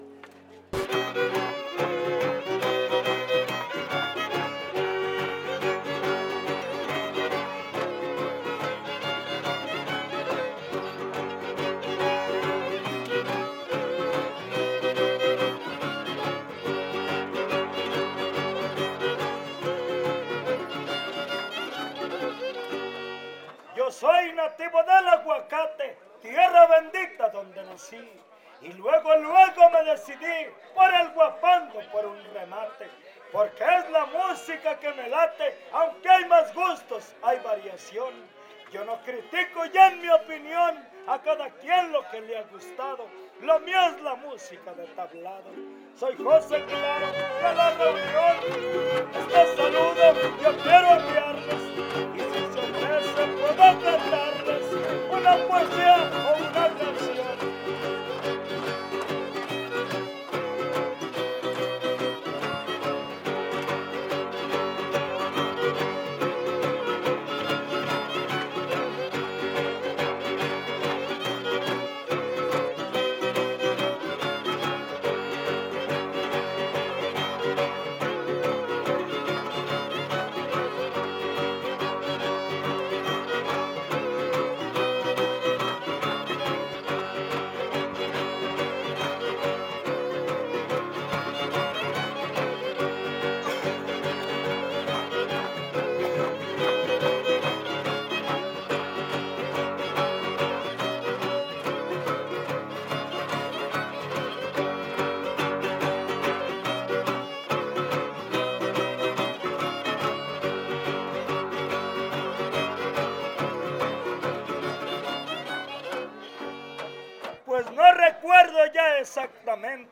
Poesía popular Huapango arribeño
Vihuela Guitarra Violín
Topada ejidal: Cárdenas, San Luis Potosí